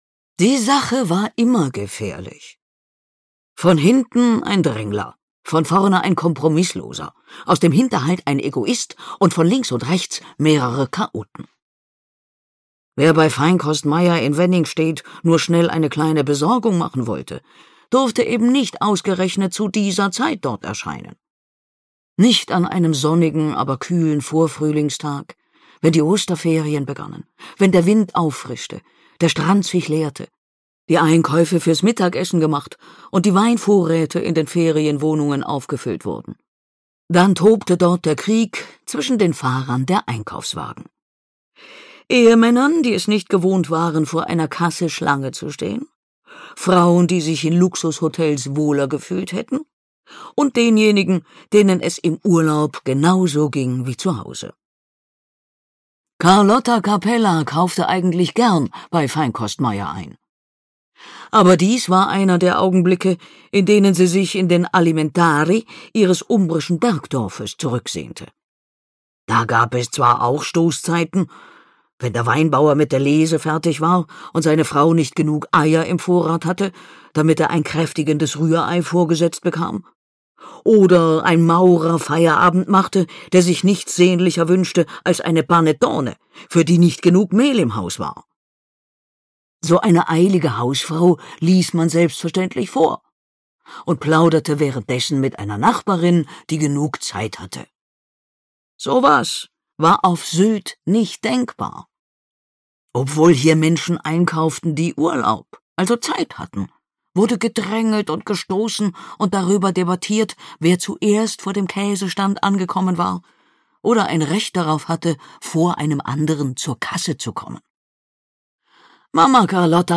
Zugvögel (Mamma Carlotta 14) - Gisa Pauly - Hörbuch